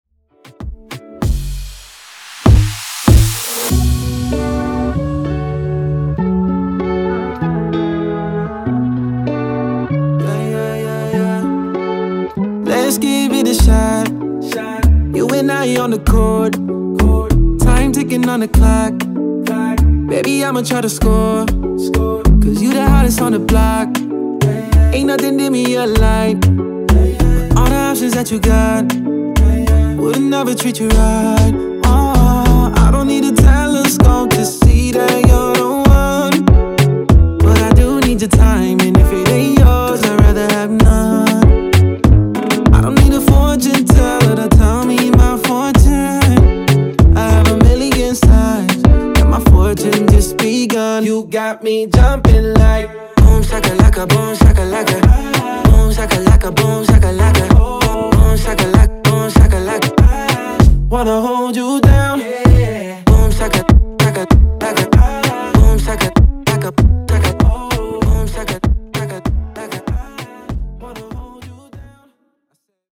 Genre: RE-DRUM Version: Clean BPM: 71 Time